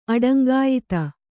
♪ aḍaŋgāyita